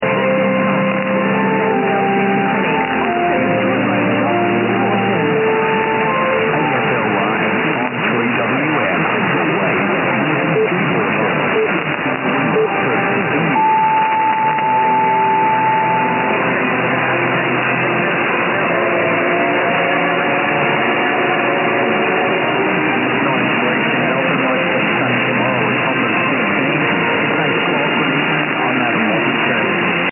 5/29　5月3回目のバイクチョイペに出動。
本日は18時少し過ぎに現地に到着し、もうすっかり慣れたΔFlagと機材を素早く設置。